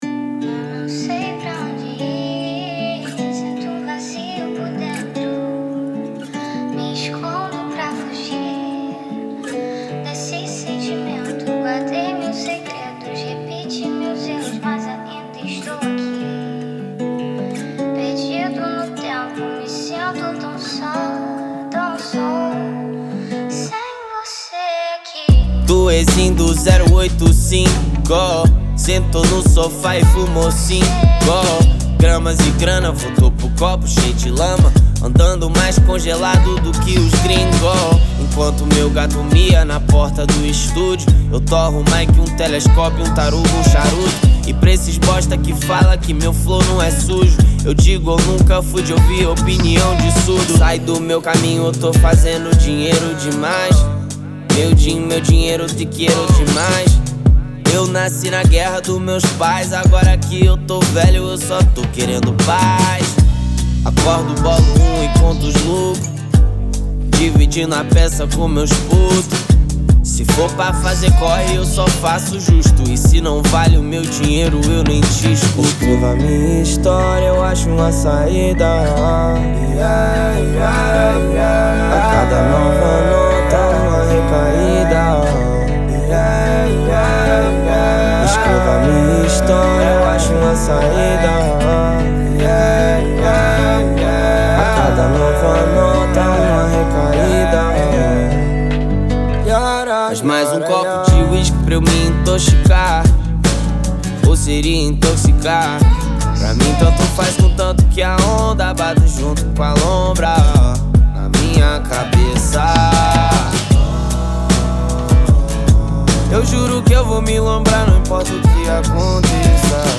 2024-04-10 15:48:28 Gênero: Trap Views